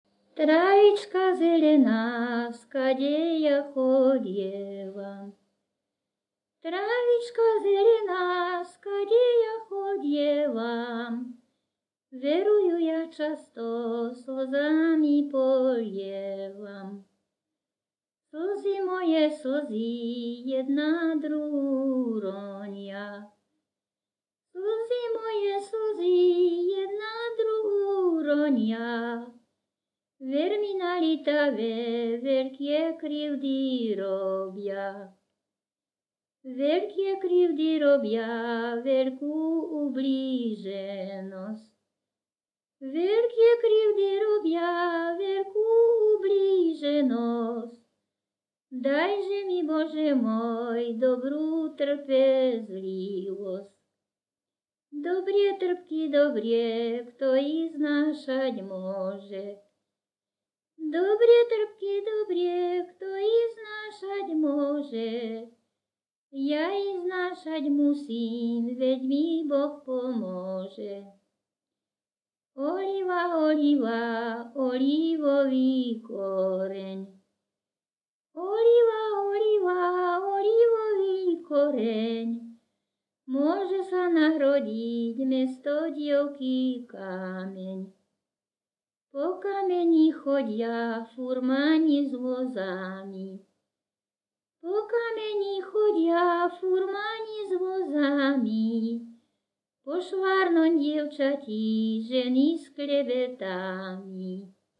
Popis sólo ženský spev bez hudobného sprievodu
Miesto záznamu Litava
11.7. Piesne pri tanci
Kľúčové slová ľudová pieseň